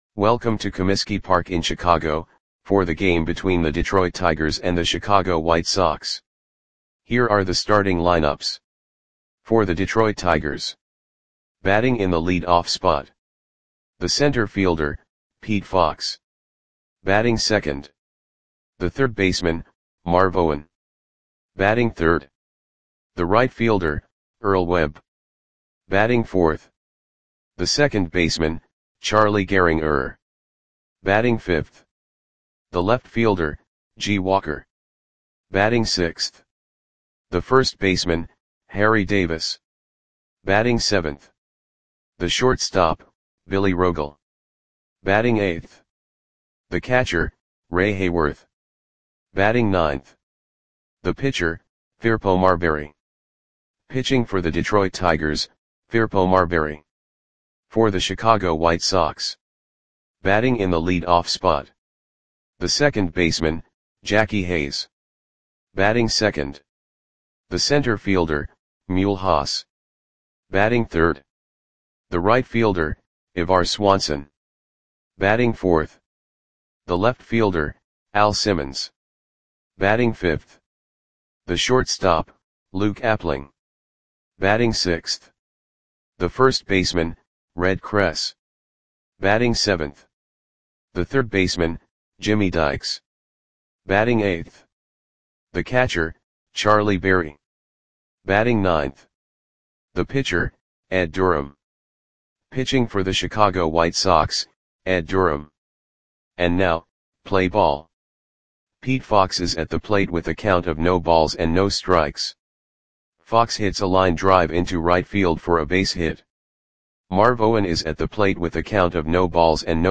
Audio Play-by-Play for Chicago White Sox on April 27, 1933
Click the button below to listen to the audio play-by-play.